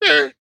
Minecraft Version Minecraft Version snapshot Latest Release | Latest Snapshot snapshot / assets / minecraft / sounds / mob / llama / idle3.ogg Compare With Compare With Latest Release | Latest Snapshot